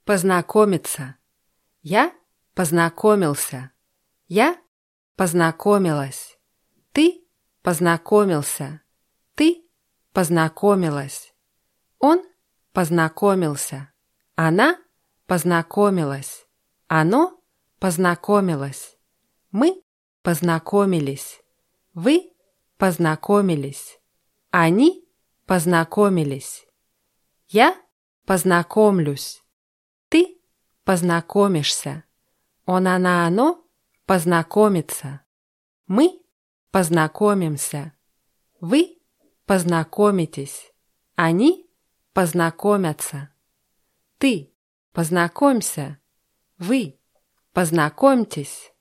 познакомиться [paznakómʲitsa]